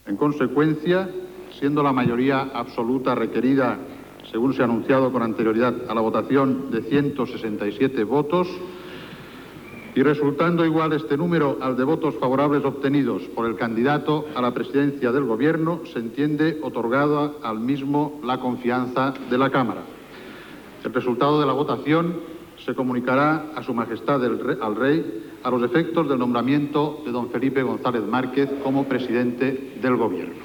El pesident del Congreso de Diputados Félix Pons proclama Felipe González per ser nomenat president el Govern espanyol (per tercera vegada)
Informatiu